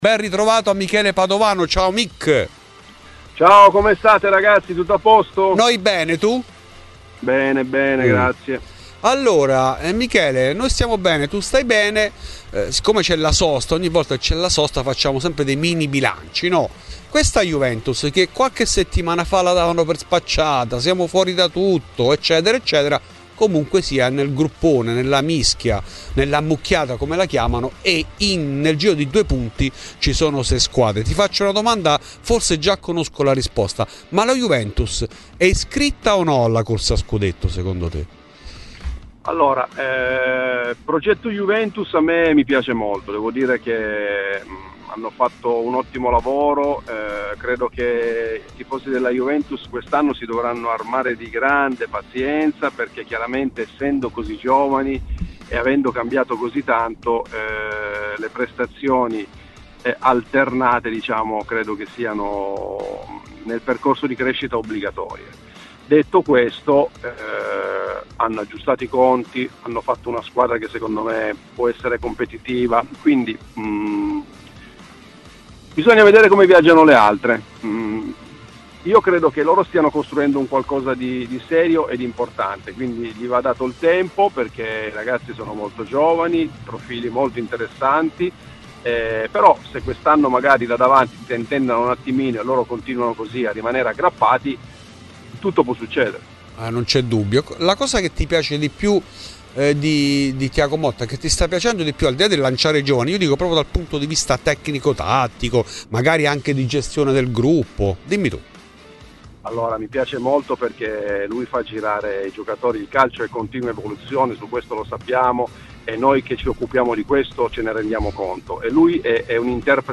In ESCLUSIVA a Fuori di Juve Michele Padovano , ex attaccante bianconero. La Juve può lottare per lo scudetto?